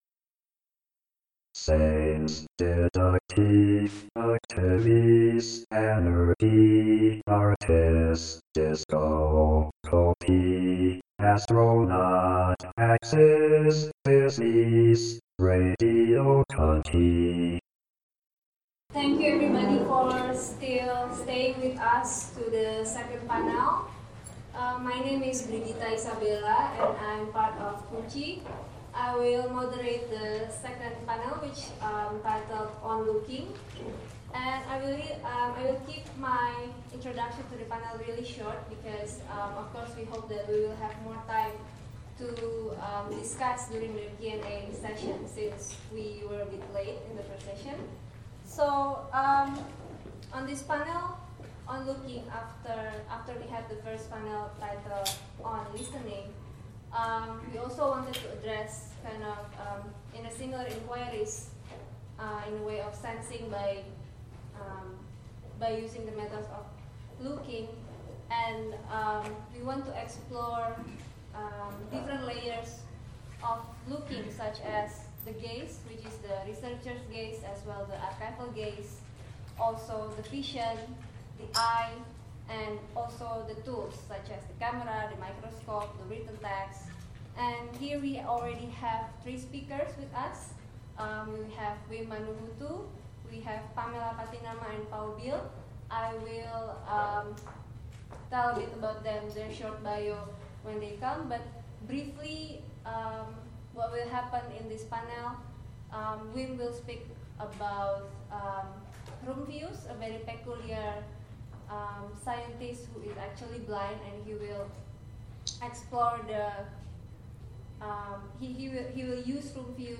The symposium was organized by Heterotropics, the Research Center for Material Culture, and the research collective KUNCI Cultural Studies Center (Yogyakarta, Indonesia) as a concluding event of their residency project at the Tropenmuseum.